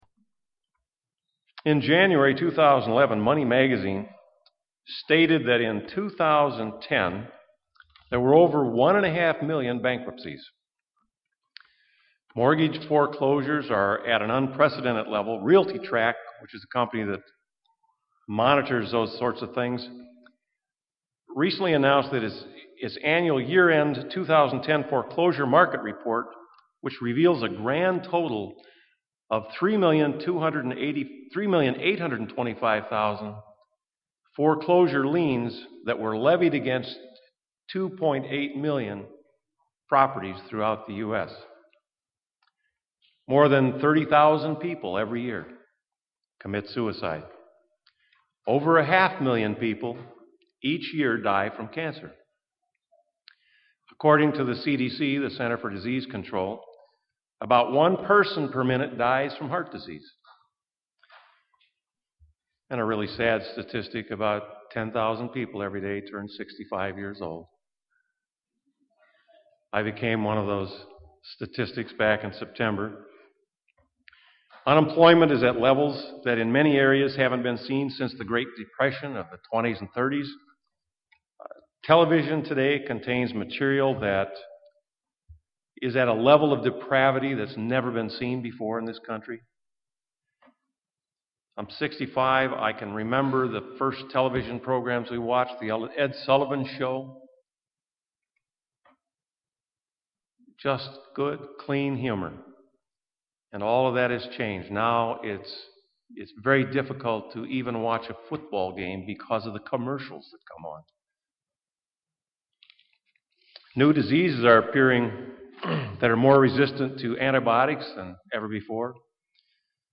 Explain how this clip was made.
Given in Milwaukee, WI